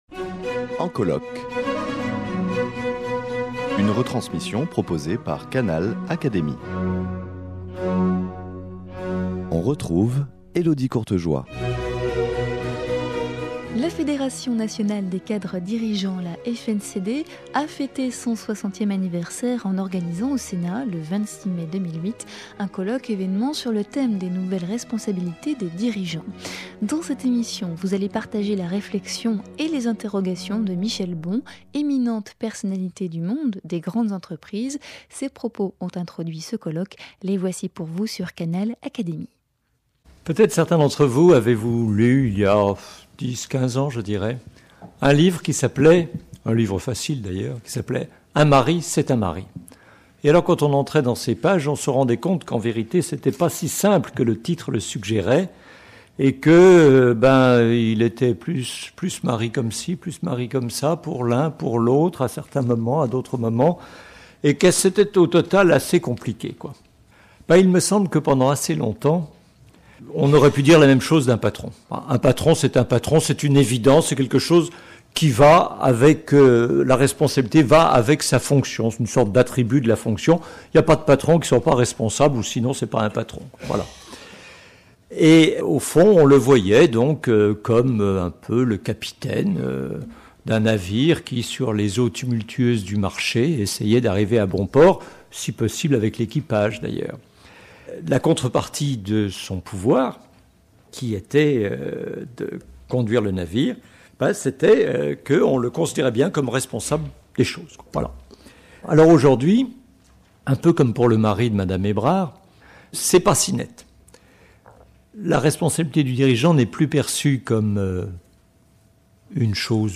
Avec les cadres dirigeants réunis au Sénat, partagez la réflexion et les interrogations de Michel Bon, éminente personnalité des grandes entreprises, sur les nouvelles responsabilités des dirigeants.